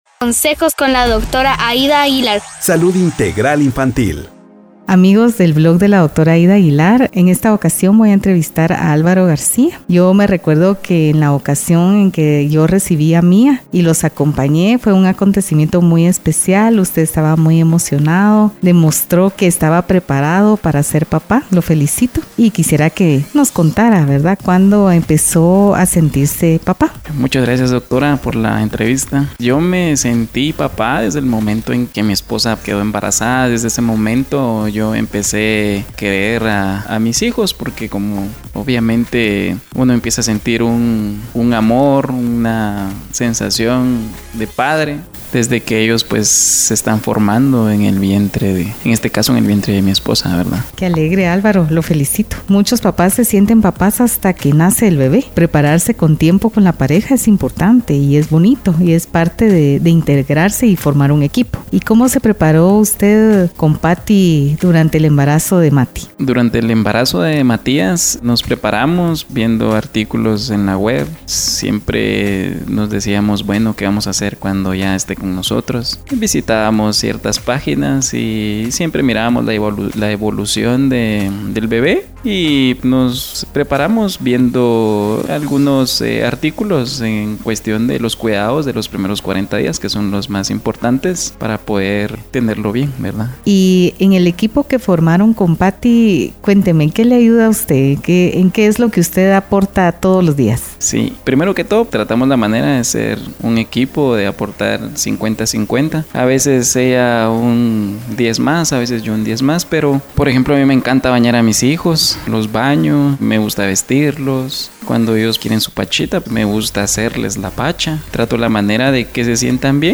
Celebrando a los ¡Súper Papás! Entrevista 3 Podcast #023